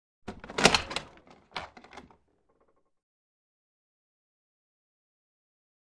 HalfGlassWoodDoor1 PE181201
Half Glass / Wood Door 1; Open With A Creak.